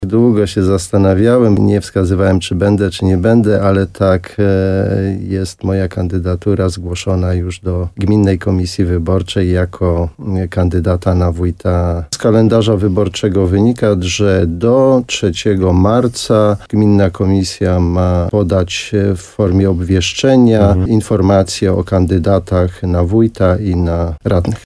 Jednym z kandydatów na wójta będzie obecny pełnomocnik gminy Janusz Opyd, który teraz jednoosobowo zarządza samorządem. O swoich zamiarach mówił w programie Słowo za Słowo na antenie radia RDN Nowy Sącz.